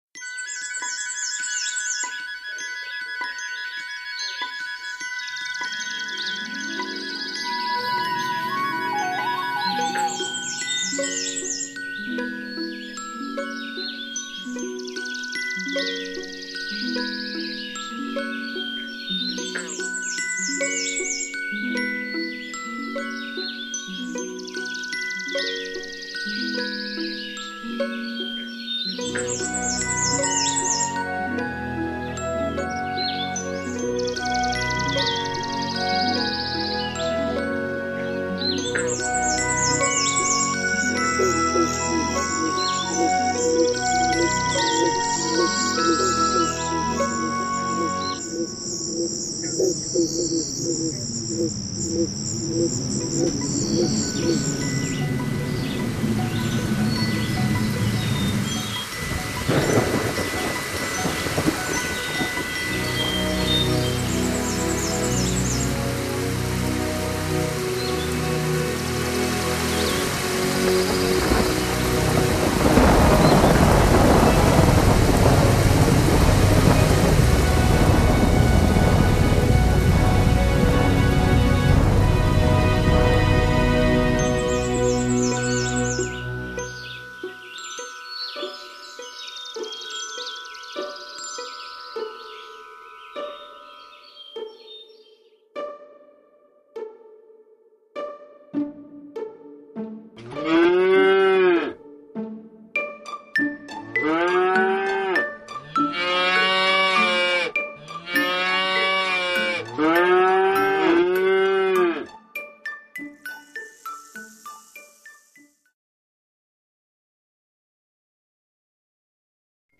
1. Zagadki dźwiękowe – Odgłosy wiosny.
Odtwarzamy  nagranie odgłosów wiosny.
odglosy_wiosny.mp3